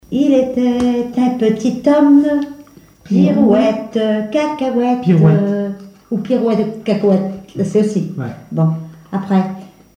Mémoires et Patrimoines vivants - RaddO est une base de données d'archives iconographiques et sonores.
Enfantines - rondes et jeux
Pièce musicale inédite